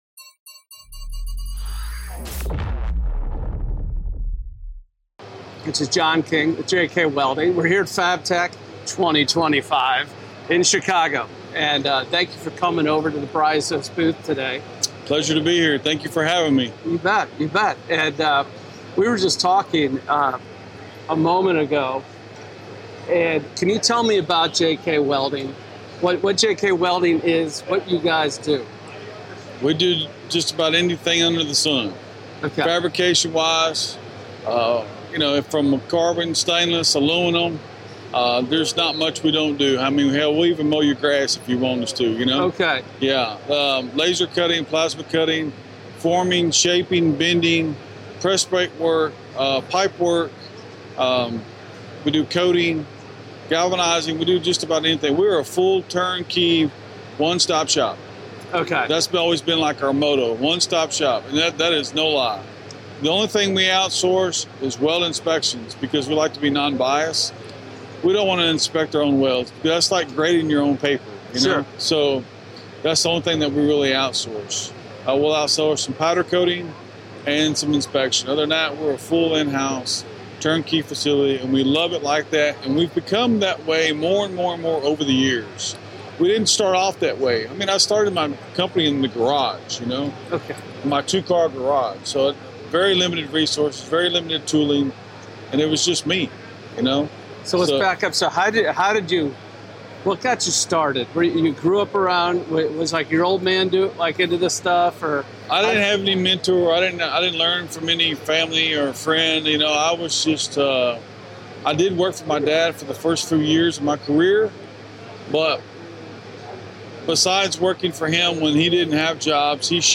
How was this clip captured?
In this episode, recorded from the show floor at FABTECH 2025